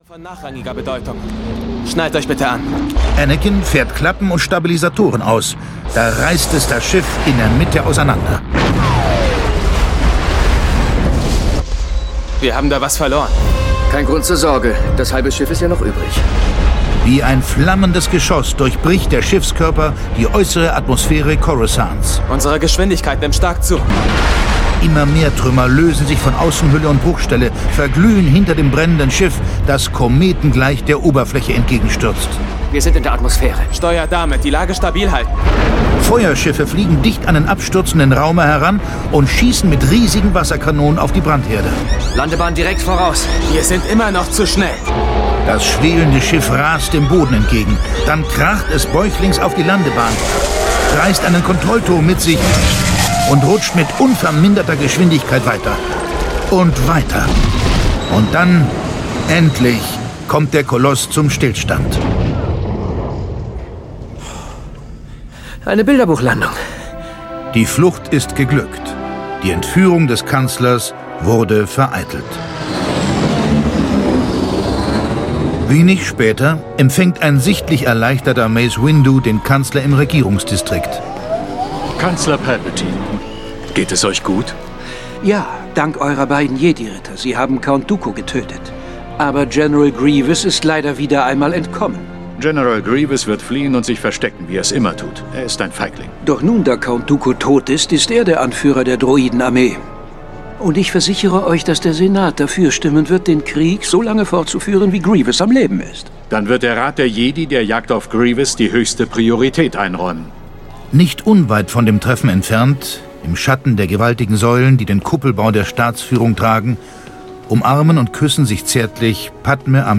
Star Wars: Die Rache der Sith (Das Original-Hörspiel zum Kinofilm) - George Lucas - Hörbuch